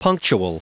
Prononciation du mot punctual en anglais (fichier audio)
Prononciation du mot : punctual